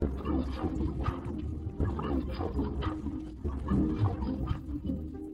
(84kB / 0:00:05 / 128kbps, 44.1kHz)creepy spellbook x3 speed.mp3
I couldn't tell what the spellbook was saying in the last episode so I isolated the audio and sped it up x3.